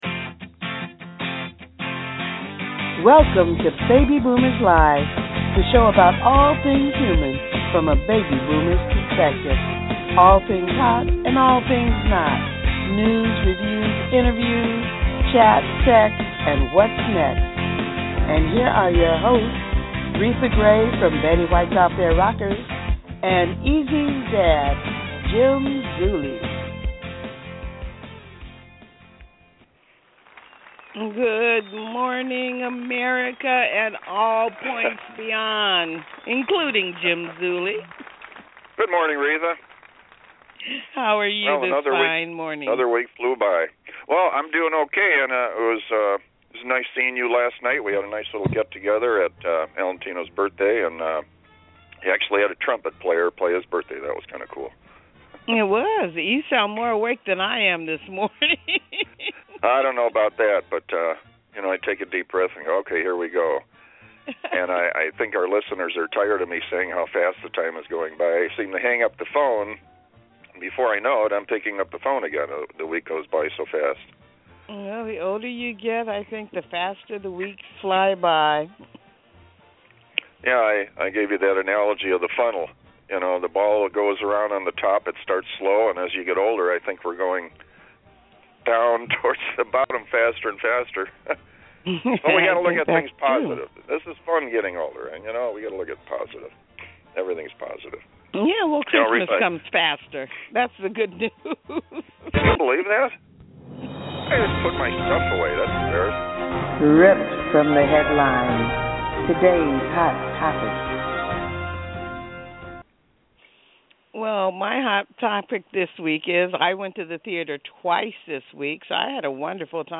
Baby Boomers Live on EZ WAY BROADCASTING With Bataan Death March Surviver